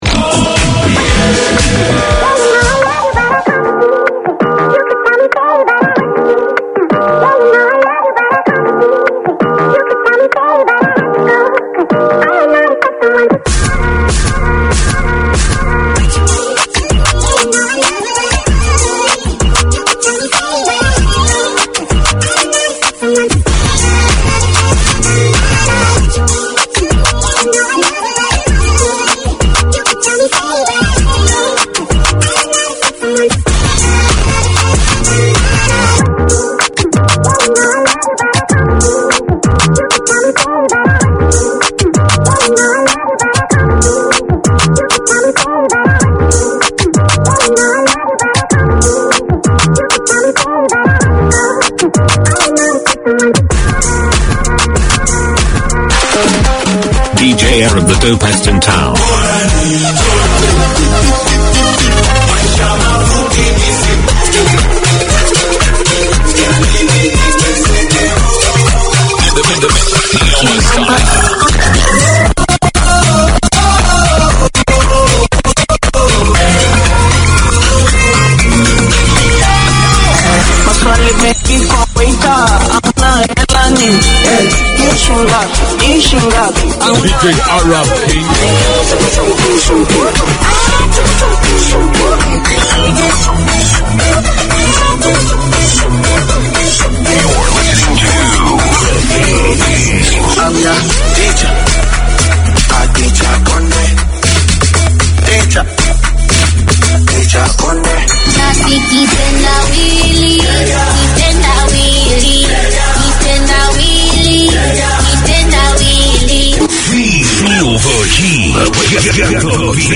In this one-off special, Planet FM presents a snapshot of Te Matatini 2023, with vox-pops and interviews undertaken in the marketplace at Ana Wai / Eden Park where the festival took place. A celebration of the best of Kapa Haka across Aotearoa New Zealand, this year's Te Matatini festival was hosted by Ngāti Whātua Ōrākei.